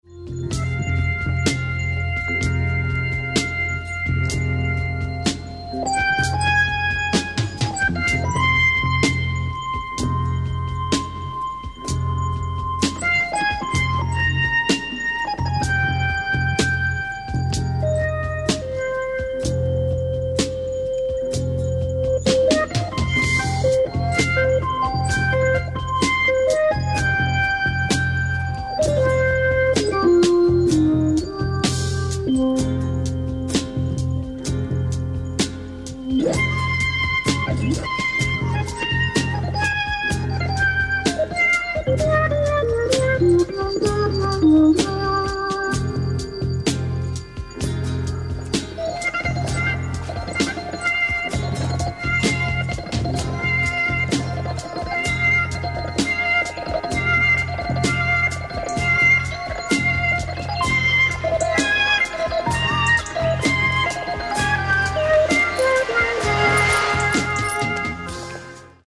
voici le solo d’orgue (qui m'a longtemps intrigué)